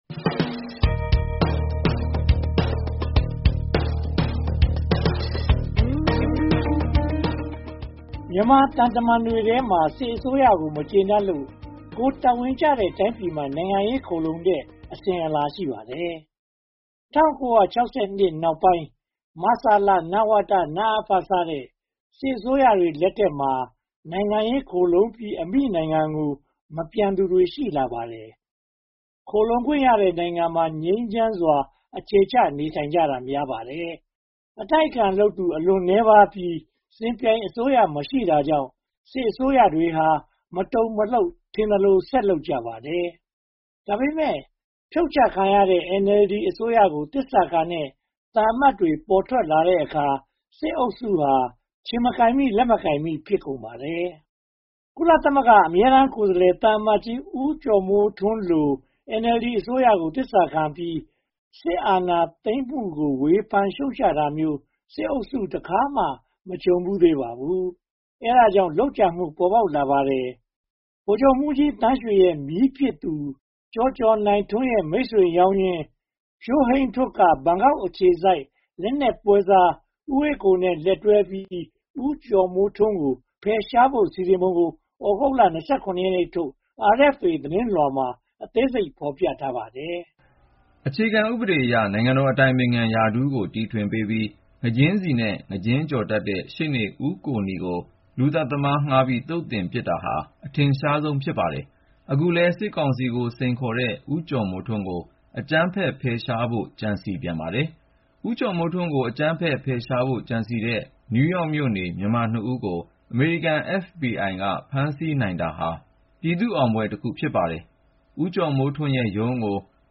သတင်းသုံးသပ်ချက်